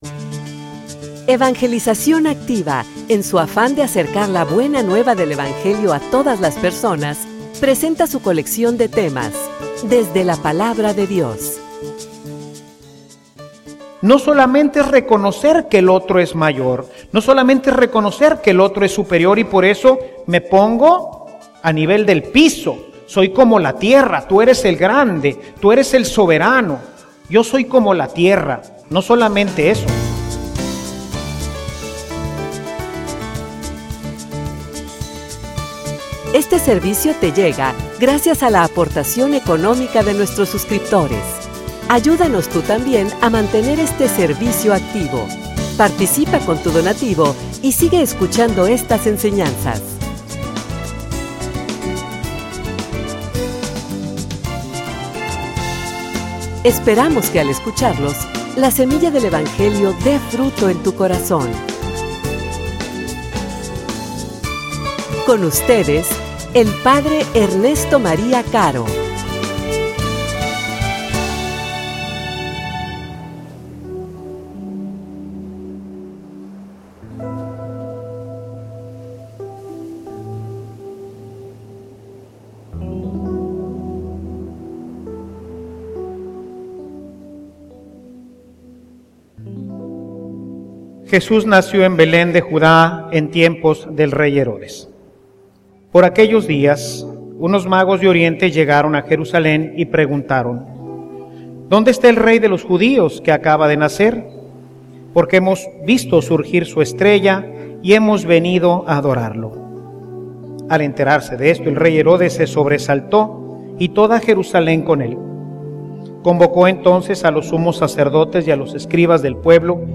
homilia_Adoracion.mp3